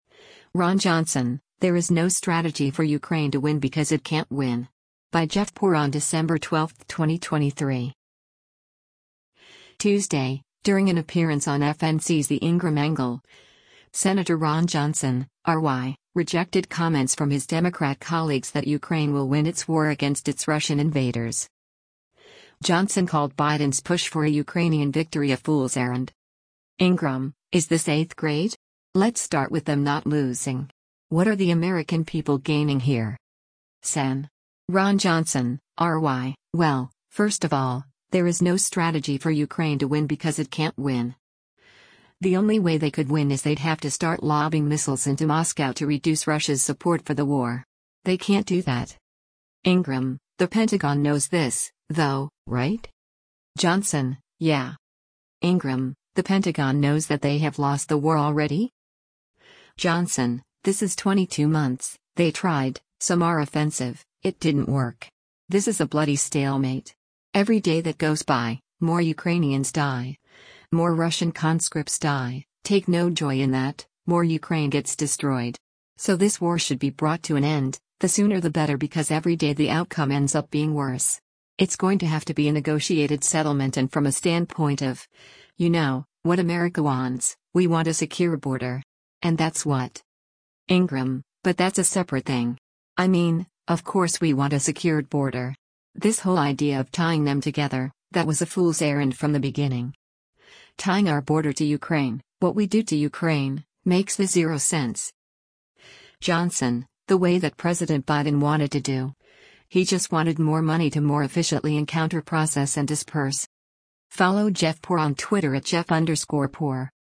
Tuesday, during an appearance on FNC’s “The Ingraham Angle,” Sen. Ron Johnson (R-WI) rejected comments from his Democrat colleagues that Ukraine will win its war against its Russian invaders.